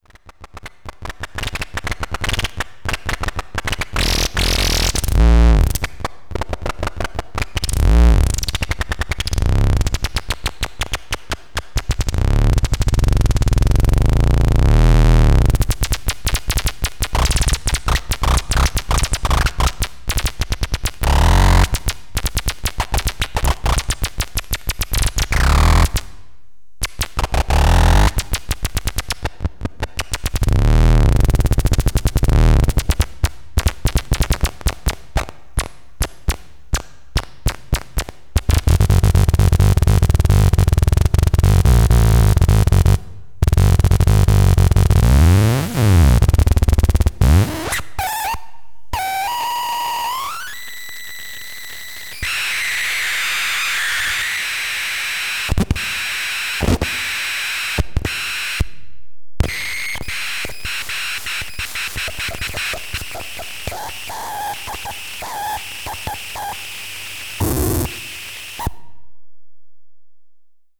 CHAOSZ (CHAOtic OSZillator), is a simple astable CMOS oscillator that can be made chaotic by power starving and variable filtering of the power supply. The sound ranges from a boring square wave to stomping locomotives to clucking chickens...